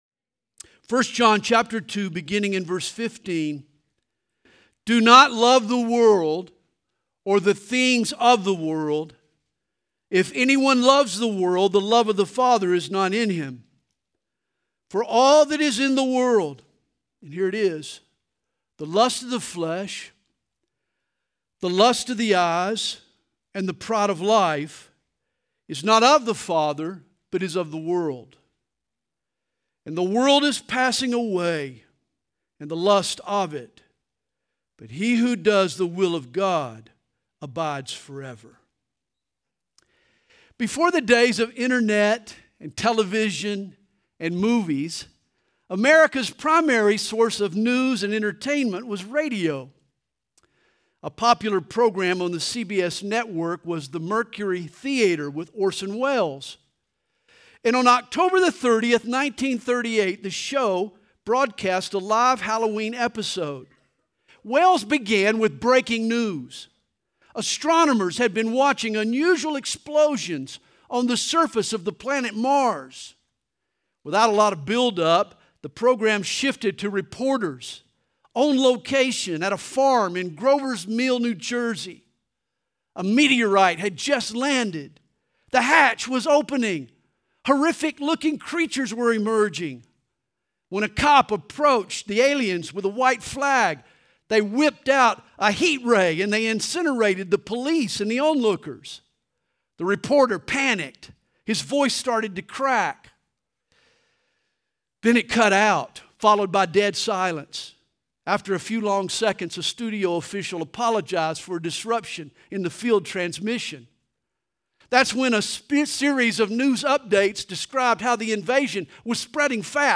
Home » Sermons » The War of the Worlds
Youth Conference